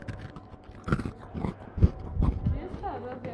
餐厅的环境声音
描述：用H1记录的餐厅人群，放大立体声。用Reaper编辑。 人们在购物商场内的一家小餐馆里聊天和吃饭的声音
标签： 环境音 餐厅 对话 ATMO 人声 背景音 氛围 餐饮 OWI 声景 一般噪音 人群 环境 气氛 ATMOS 背景声 城市 实地录音
声道立体声